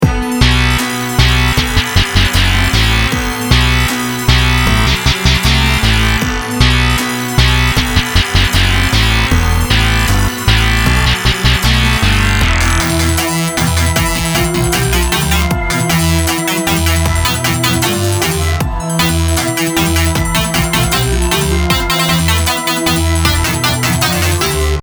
A jam I'd designed for the hero's return to homelands to in surprise find chaos, decimation and the ravage of opposing forces.Including the original version, circa 2017.